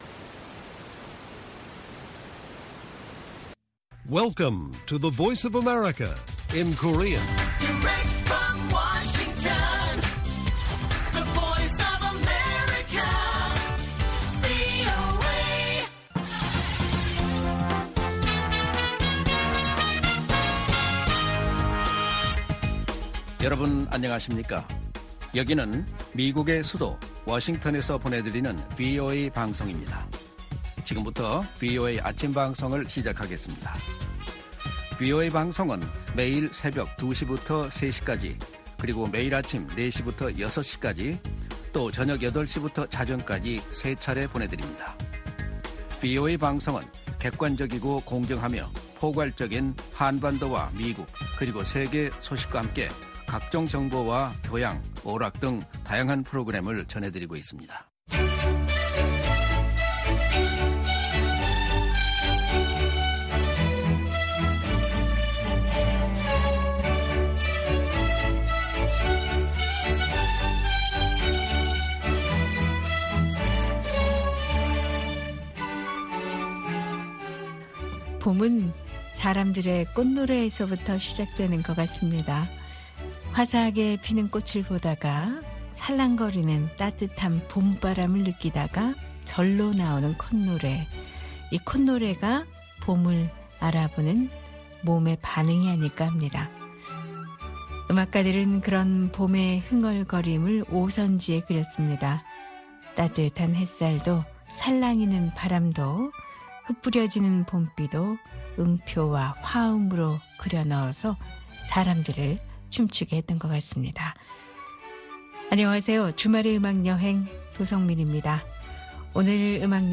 VOA 한국어 방송의 일요일 오전 프로그램 1부입니다.